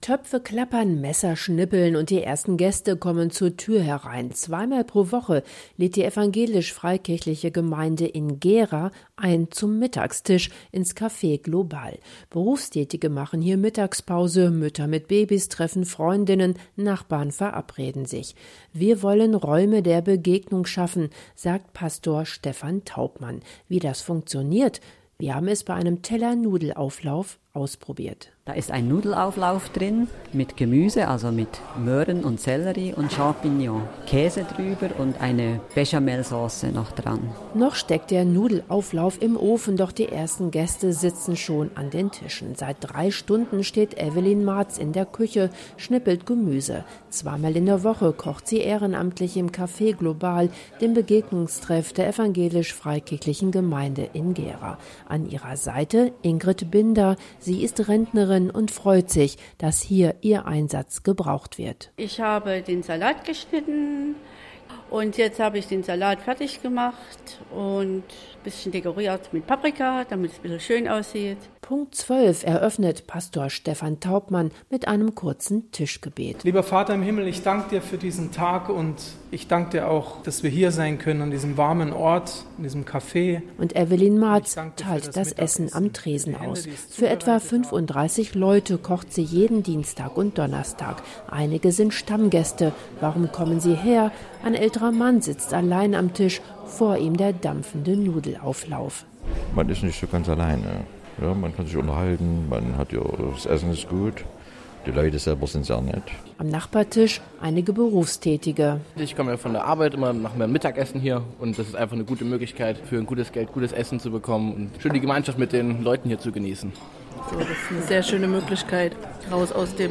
Töpfe klappern, mit Messern wird geschnippelt – und die ersten Gäste kommen zur Tür herein: zweimal pro Woche lädt die Evangelisch-freikirchliche Gemeinde in Gera in Thüringen ein zum Mittagstisch ins „Café Global“. Berufstätige machen hier Mittagspause, Mütter mit Babys treffen Freundinnen, Nachbarn verabreden sich.